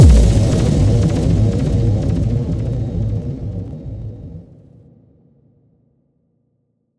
Impact 08.wav